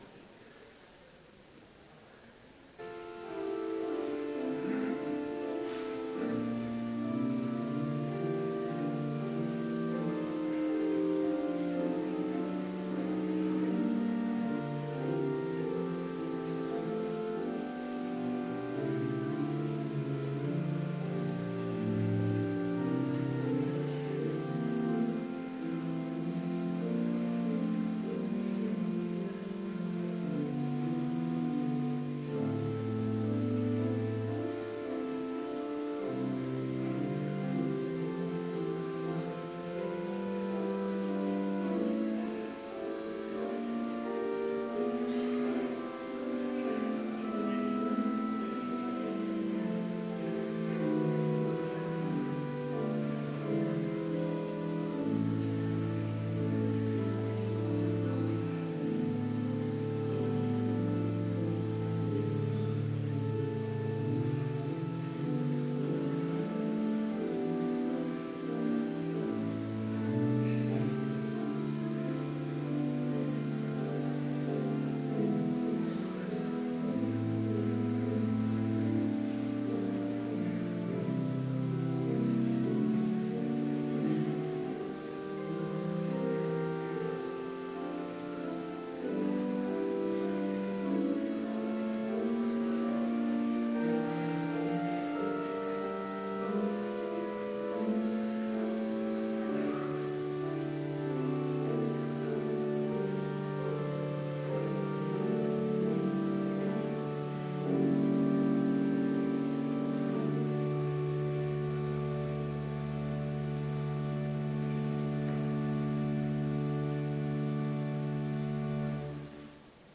Complete service audio for Chapel - March 16, 2016
Sermon Only